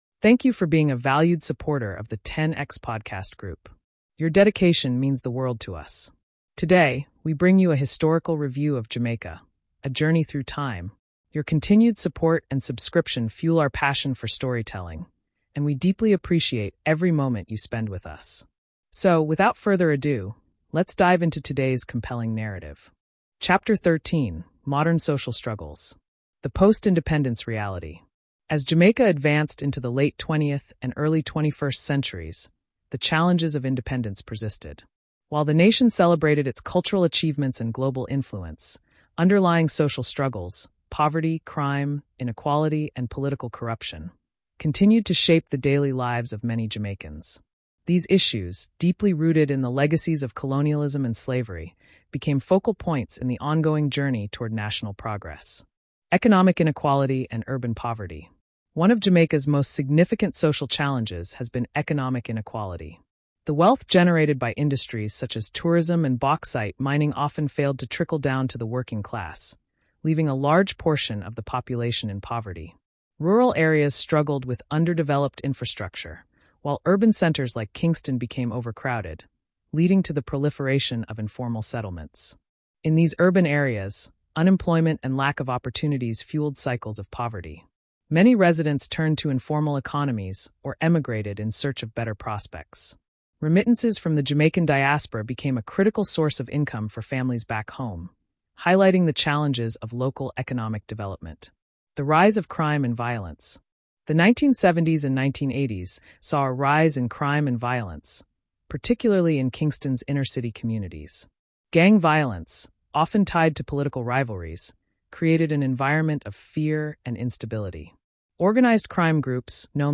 This rich narrative blends expert insights, historical storytelling, and authentic Caribbean music, giving voice to the island’s heritage, art, and Afro-Caribbean identity.